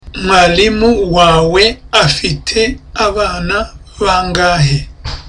Dialogue:
(Calmly)